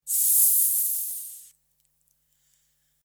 Siseo de serpiente
voz animal serpiente siseo